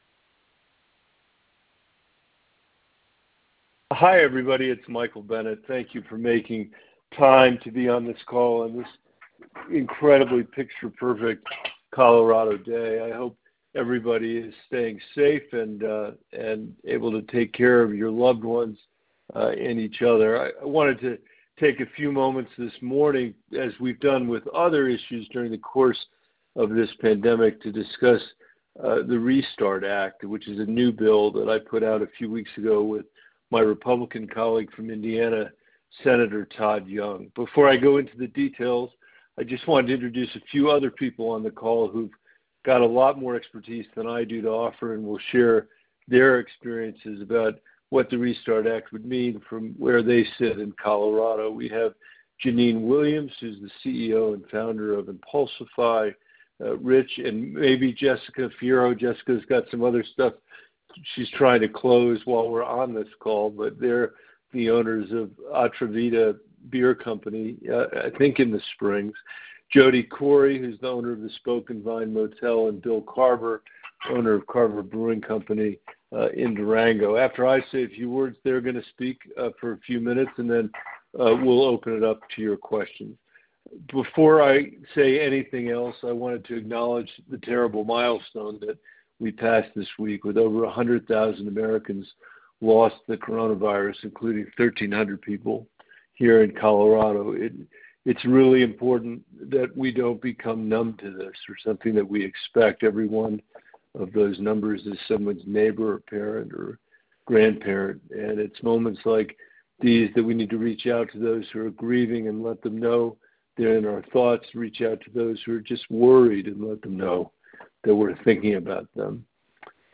Listen to Bennet’s Panel Discussion HERE
Denver – Today, Colorado U.S. Senator Michael Bennet and several business owners from across Colorado who have been hit the hardest by the Coronavirus Disease 2019 (COVID-19) public health and economic crisis discussed their needs as the economy begins to reopen, and how Bennet’s legislation with U.S. Senator Todd Young (R-Ind.), the Reviving the Economy Sustainably Towards a Recovery in Twenty-twenty (RESTART) Act, can help.